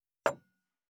233,机に物を置く,テーブル等に物を置く,食器,
コップ